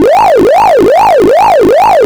The generated wave will likely produce a cleaner spectrogram to analyze, but they are there for you to use as you see fit.
siren1-generated.wav